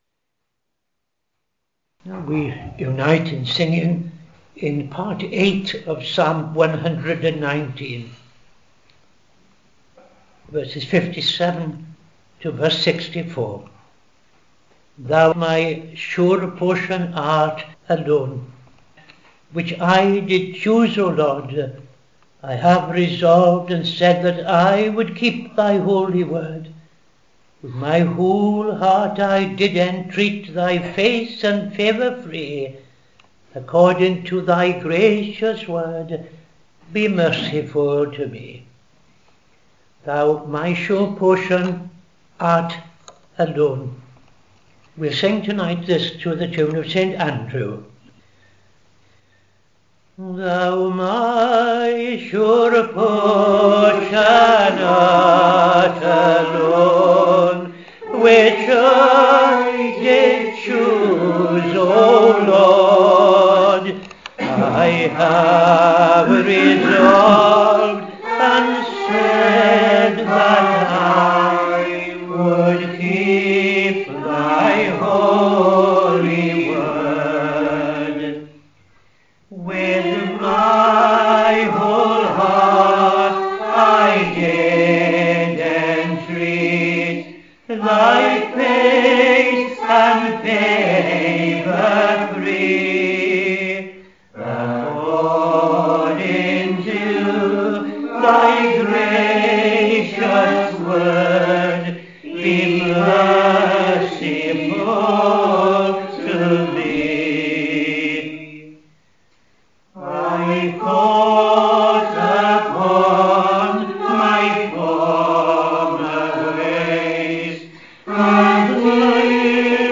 Evening Service - TFCChurch
5.00 pm Evening Service Opening Prayer and O.T. Reading I Chronicles 9:10-26